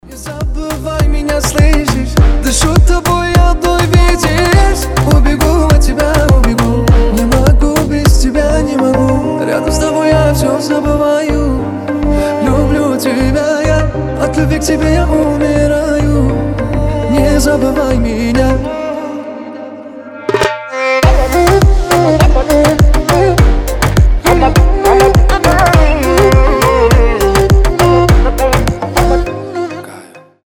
• Качество: 320, Stereo
мужской голос
восточные